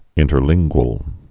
(ĭntər-lĭnggwəl)